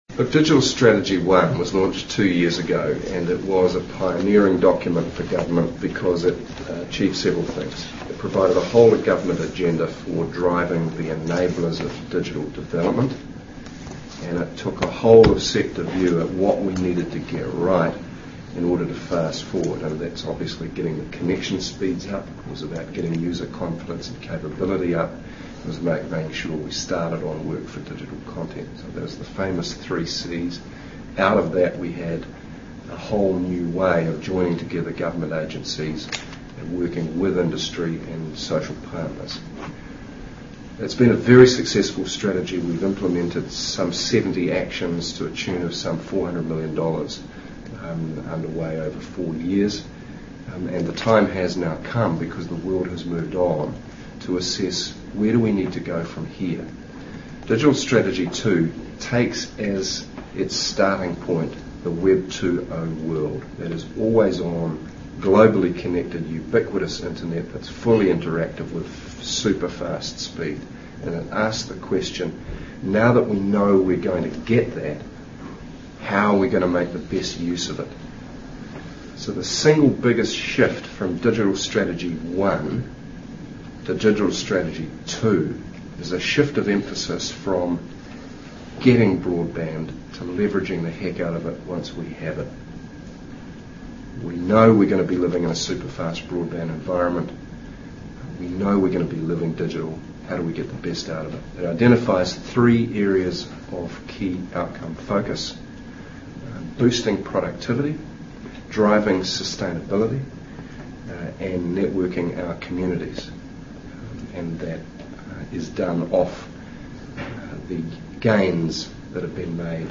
In this podcast of this morning’s Beehive press conference, Cunliffe explains why he thinks Labour’s plan to stimulate broadband is better than National’s and outlines some of the community and business initiatives the Government plans to undertake to kickstart broadband uptake in key sectors.